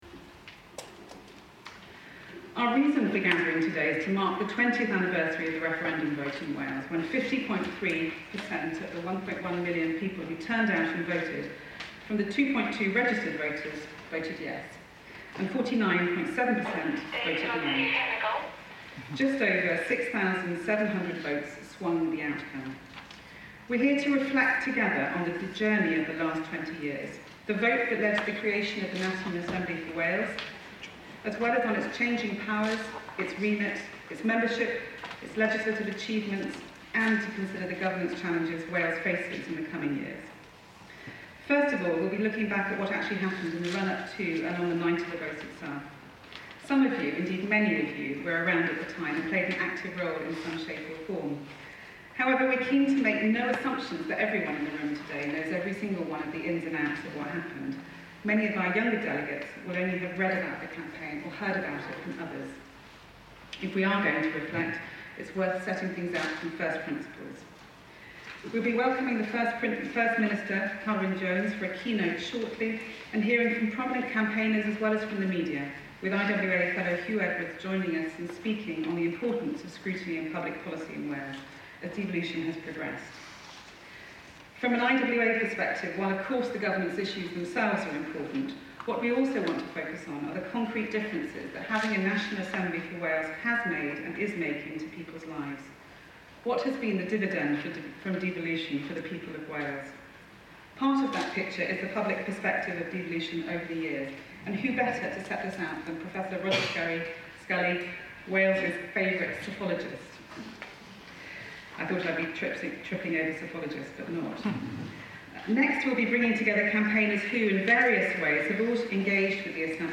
September 2017: What happened in the 1997 devolution referendum? Discussion at "Wales Said Yes"